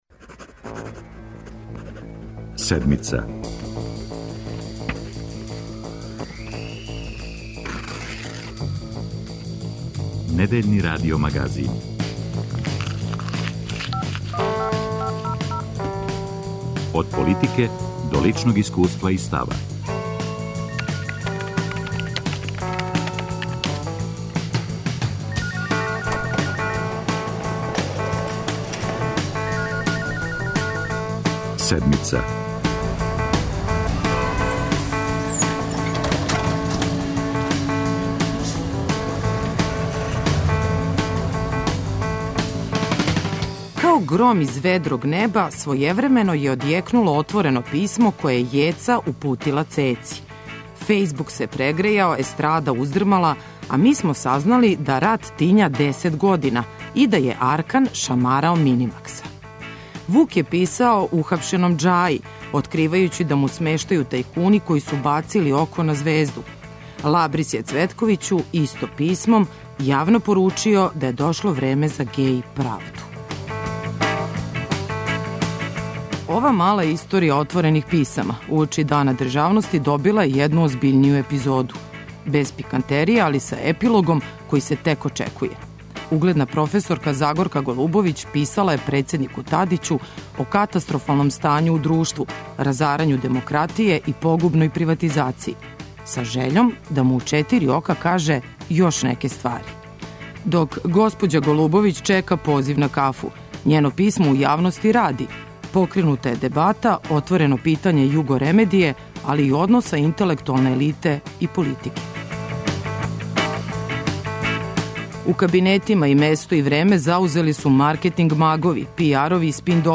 Ексклузивно за Седмицу говори известилац у Парламентарној скупштини Савета Европе Жан Шарл Гардето.